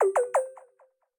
ChargingStarted_Fun.ogg